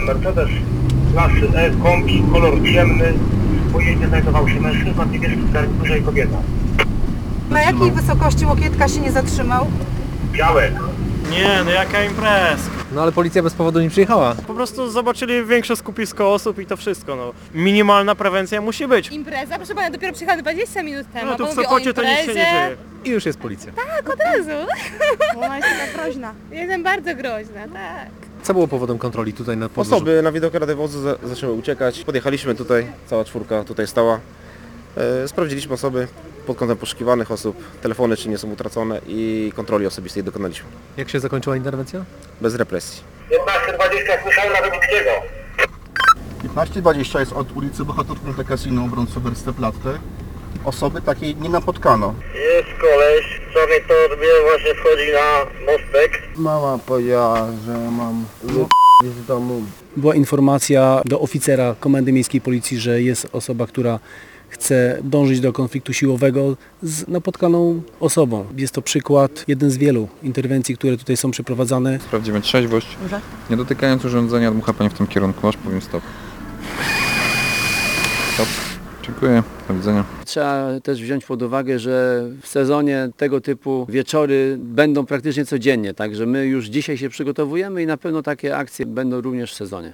Pijaństwo, zniewaga służb, ucieczka przed patrolem…Nasz reporter przez jedną noc towarzyszył policjantom z Sopotu